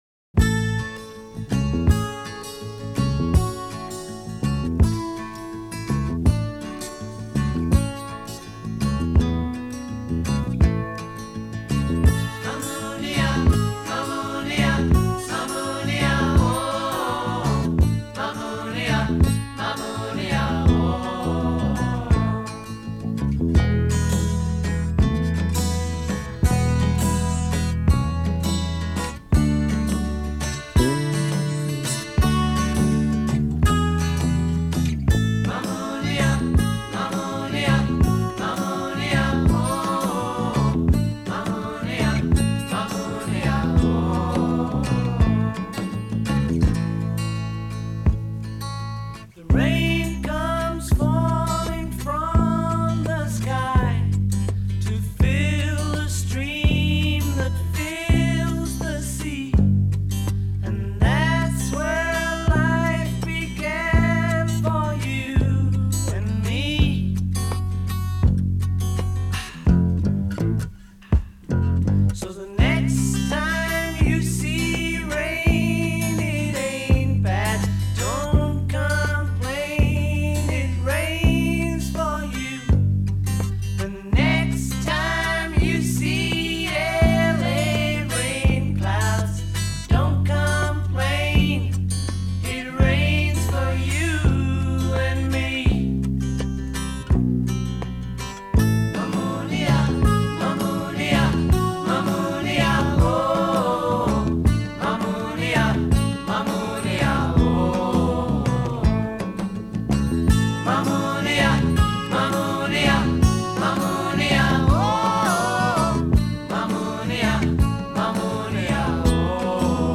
an African rhythm